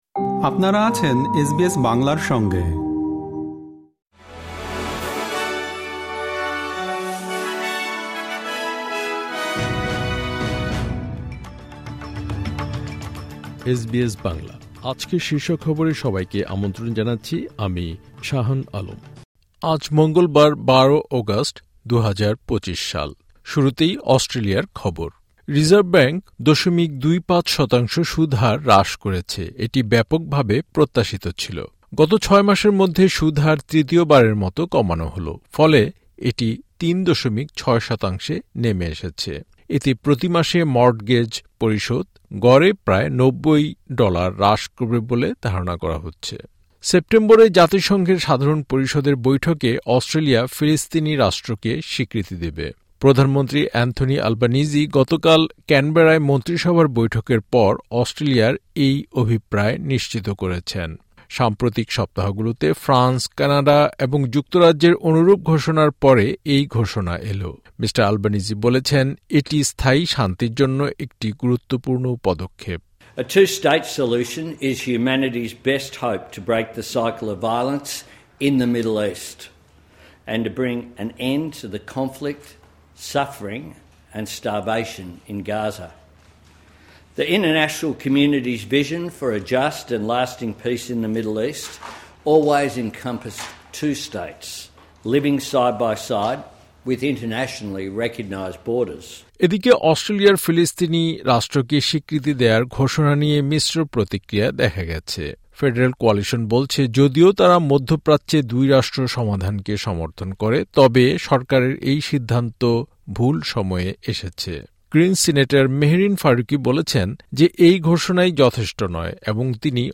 এসবিএস বাংলা শীর্ষ খবর: ১২ অগাস্ট, ২০২৫